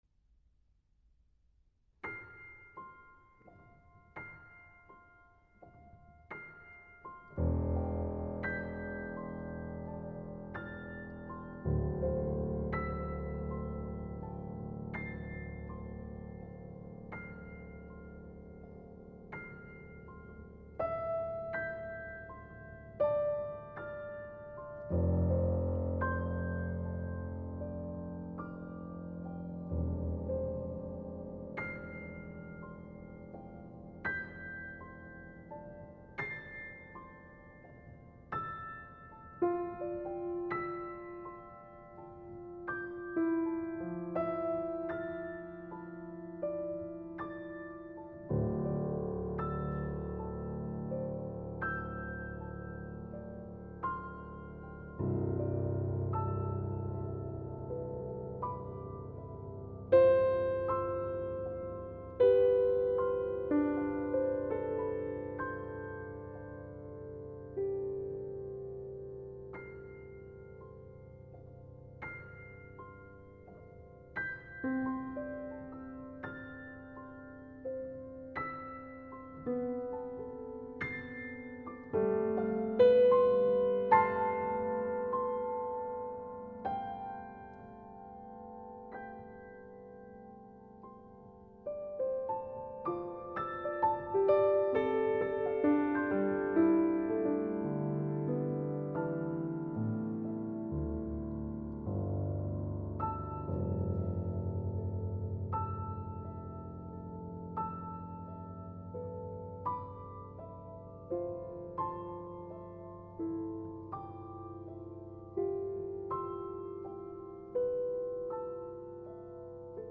Original piano compositions and classical interpretations
Recorded in the historic Holywell Music Room, Oxford